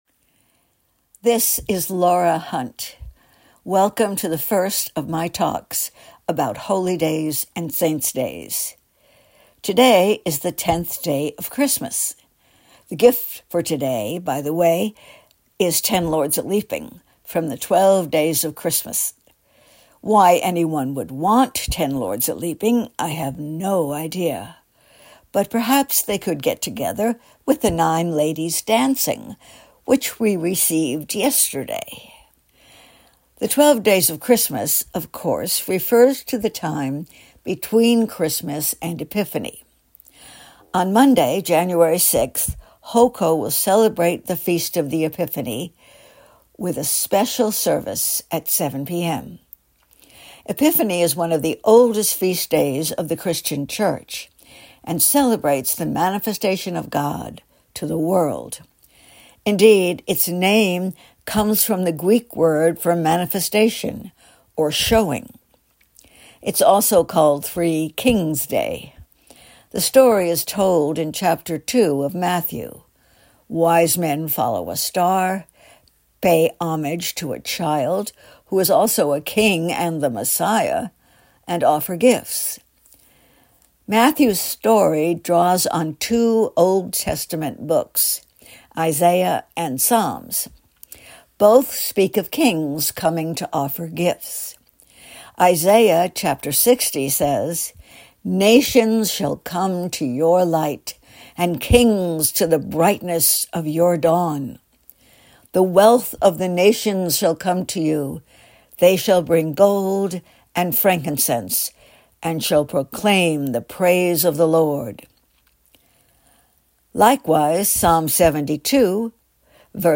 talk on Epiphany.